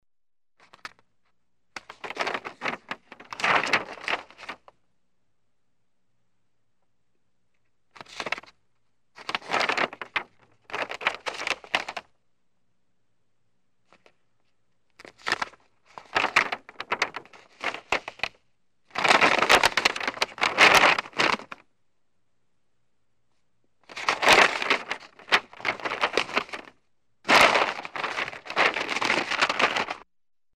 Газеты звуки скачать, слушать онлайн ✔в хорошем качестве